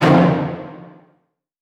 Scare_v2_wav.wav